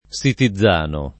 [ S iti zz# no ]